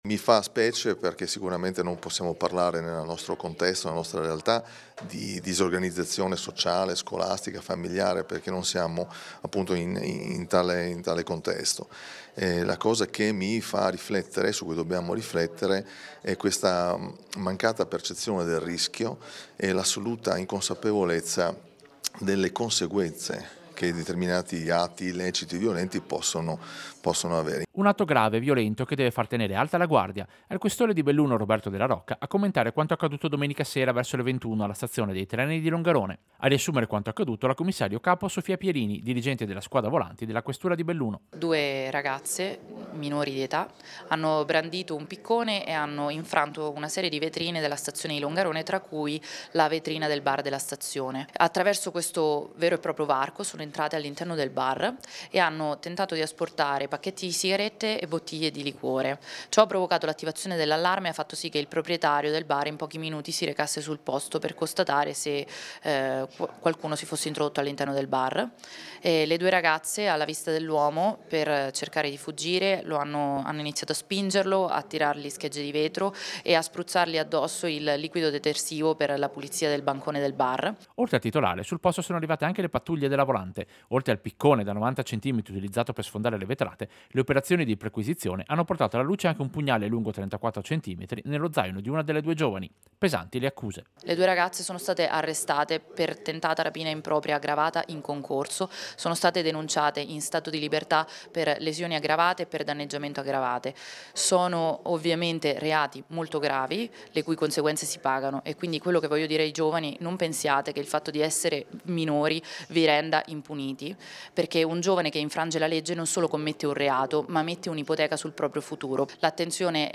Servizio-Tentata-rapina-stazione-Longarone-minorenni.mp3